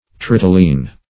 tritylene - definition of tritylene - synonyms, pronunciation, spelling from Free Dictionary Search Result for " tritylene" : The Collaborative International Dictionary of English v.0.48: Tritylene \Tri"tyl*ene\, n. (Chem.)